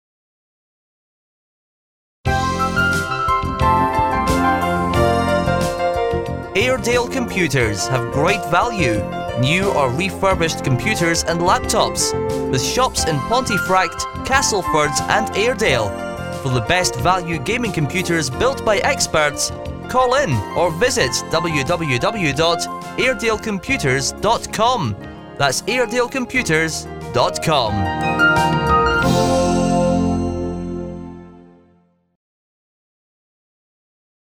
This is the new voiceover for our latest Radio advert on Chat and Spin
AIREDALE COMPUTERS ADVERT.mp3